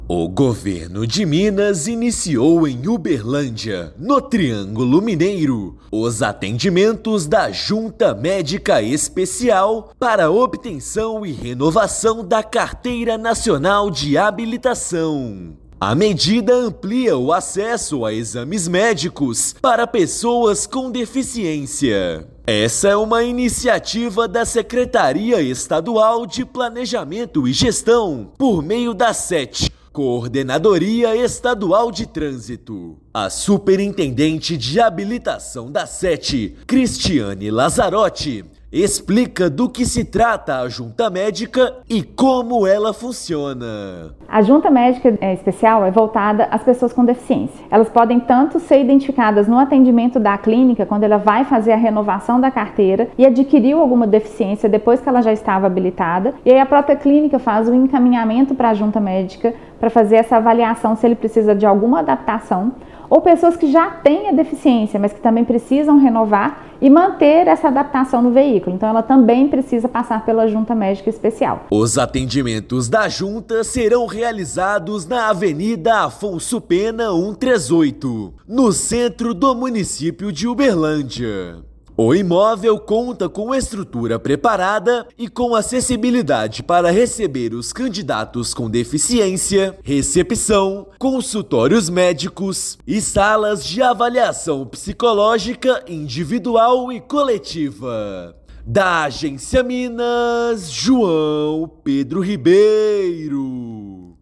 Iniciativa garante o atendimento mais próximo da população do Triângulo Mineiro, que antes precisava se deslocar até Belo Horizonte. Ouça matéria de rádio.